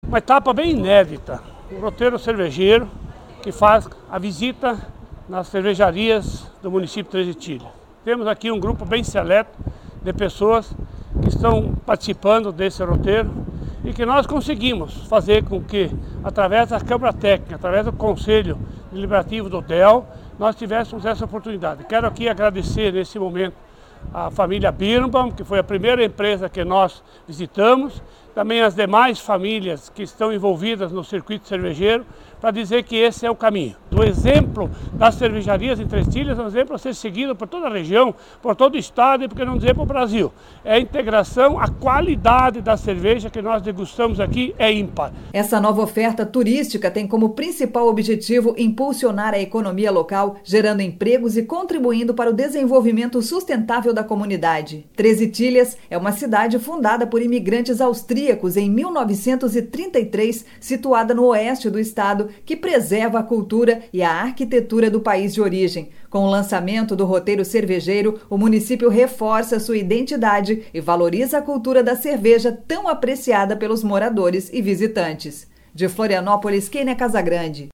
O prefeito de Treze Tílias, Rudi Ohlweiler, comentou que a iniciativa é uma oportunidade de integração para que todos possam mostrar seus produtos para os moradores e turistas: